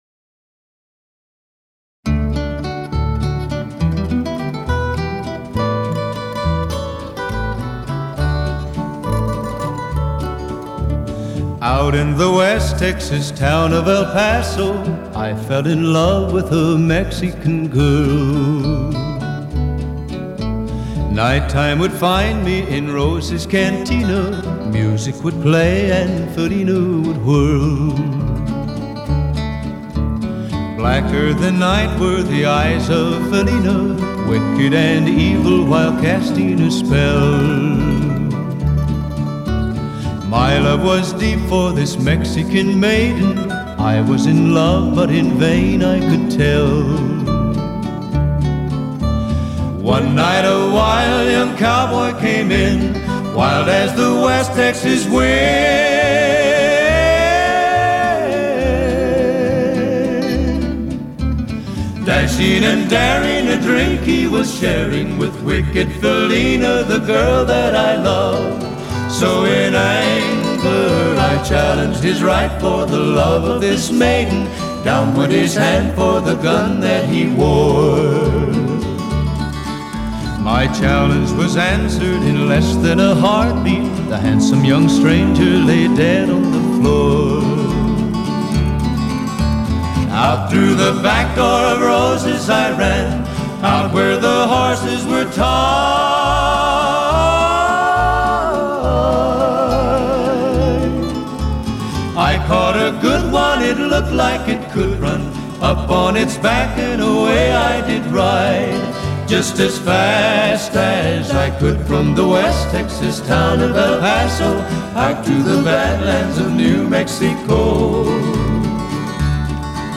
Style: Oldies 50's - 60's / Rock' n' Roll / Country